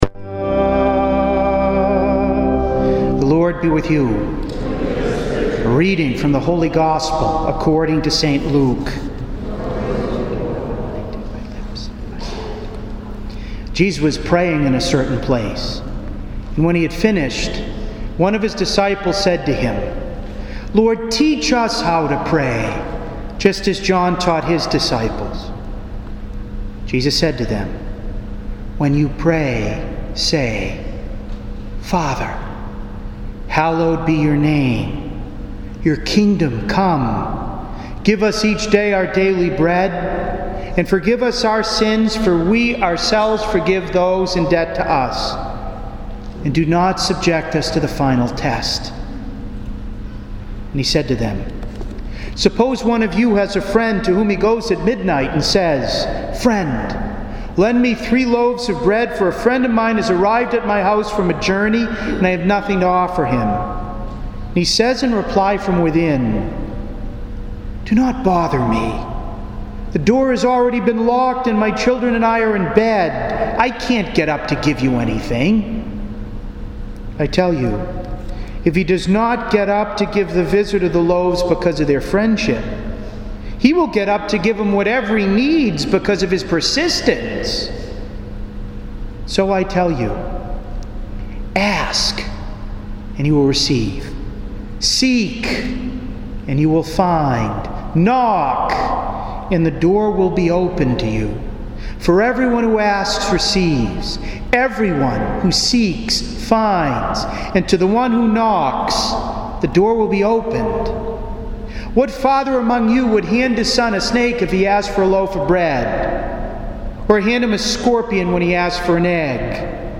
To listen to an audio of this homily, please click below: